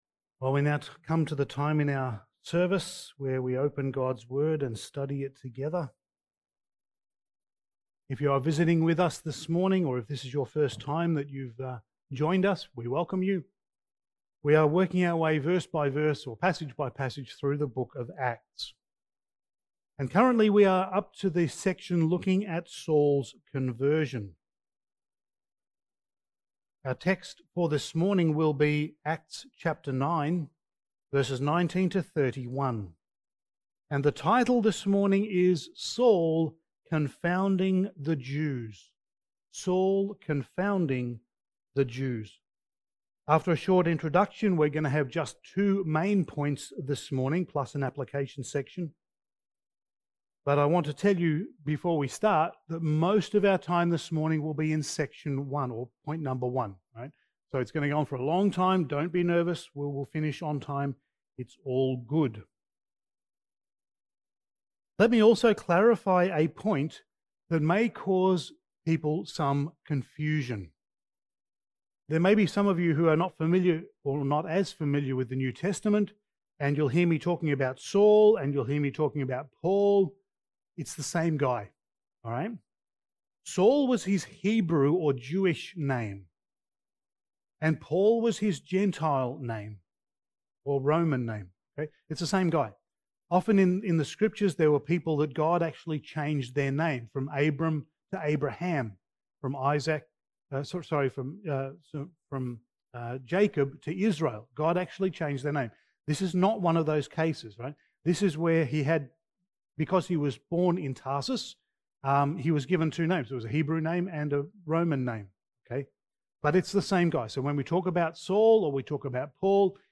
Passage: Acts 9:19-31 Service Type: Sunday Morning